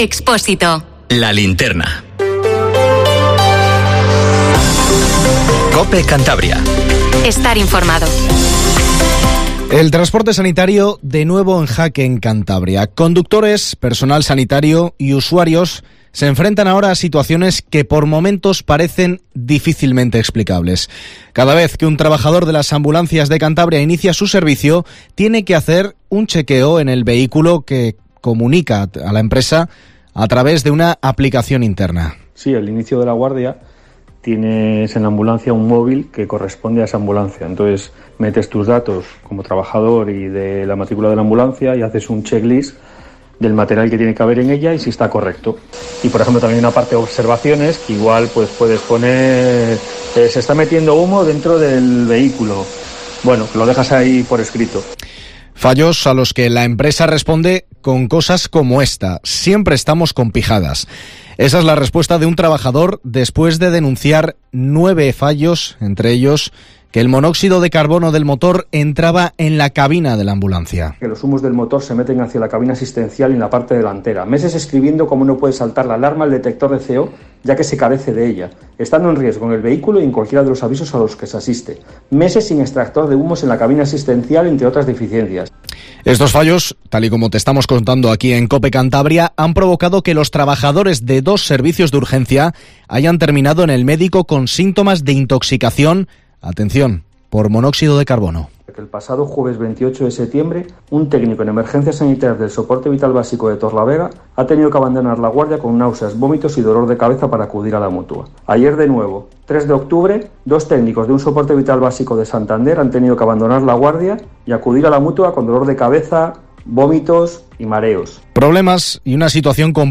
Informativo LA LINTERNA en COPE CANTABRIA 19:50